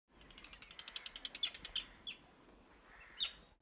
دانلود آهنگ جنگل 24 از افکت صوتی طبیعت و محیط
دانلود صدای جنگل 24 از ساعد نیوز با لینک مستقیم و کیفیت بالا
جلوه های صوتی